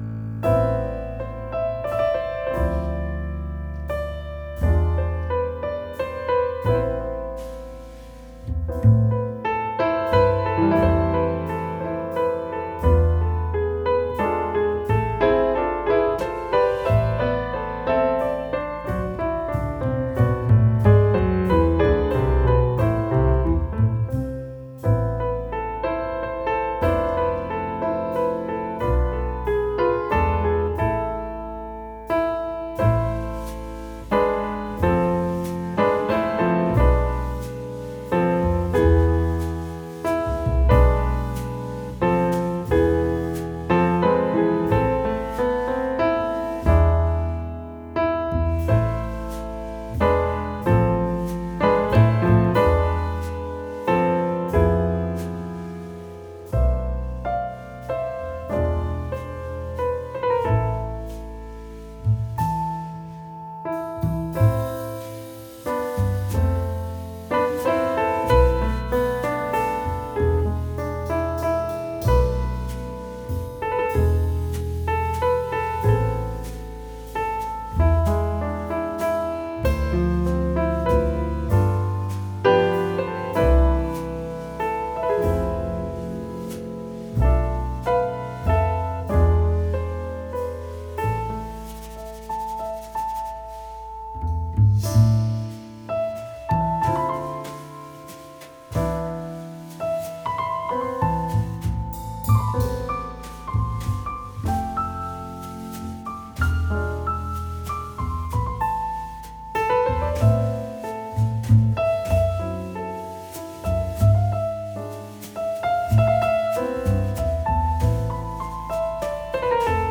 jazz pianist